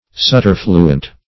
Subterfluent \Sub*ter"flu*ent\